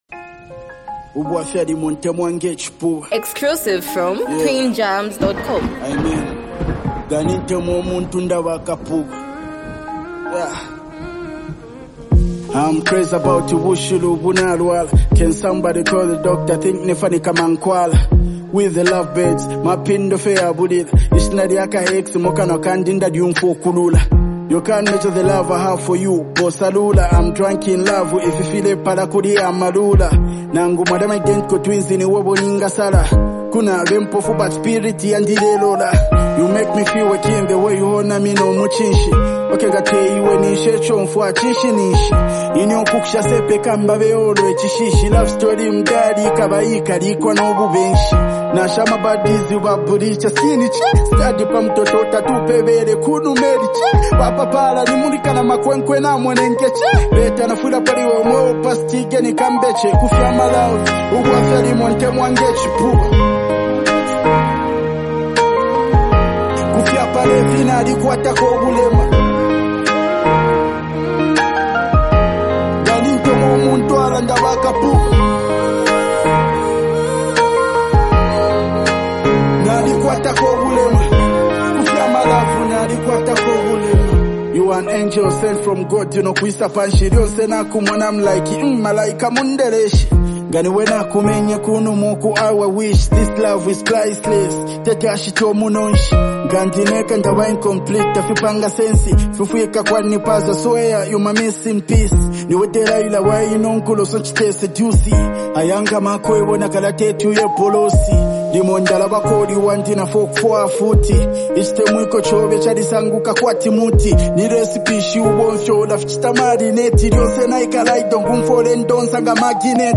is a vibrant and energetic track